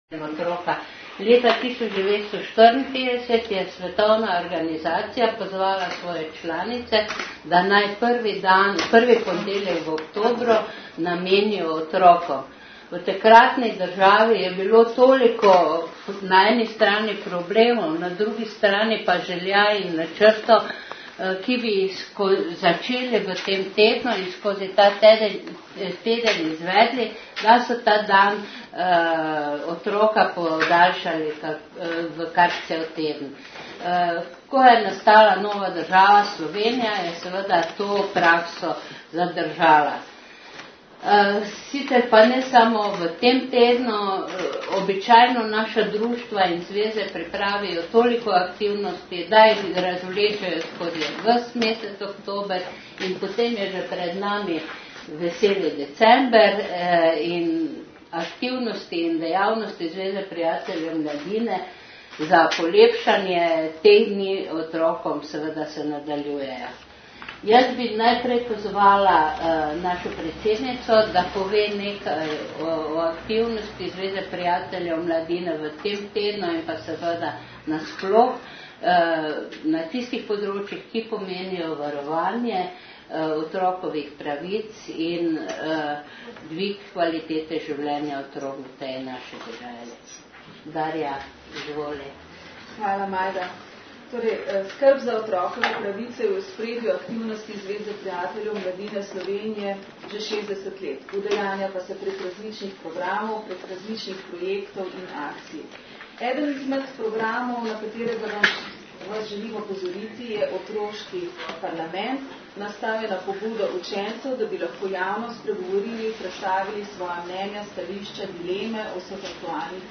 Celoten posnetek novinarske konference lahko poslušate tukaj in tukaj.